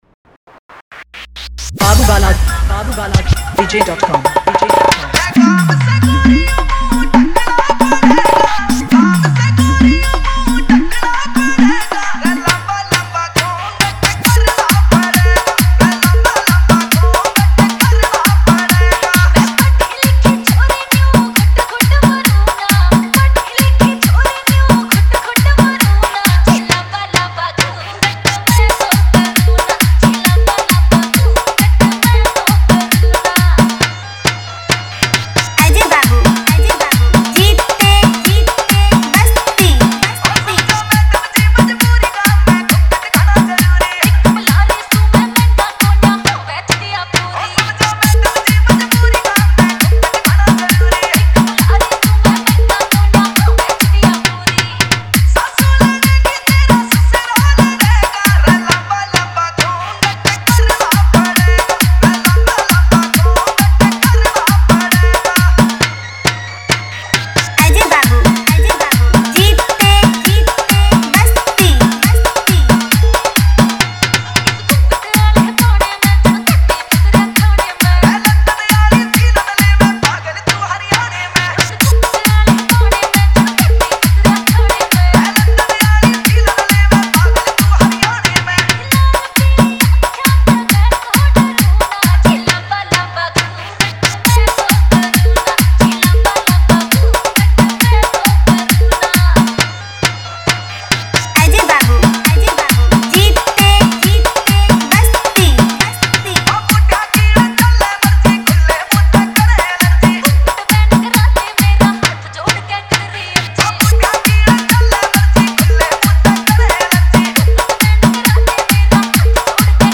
Category : Bhojpuri Wala Dj Remix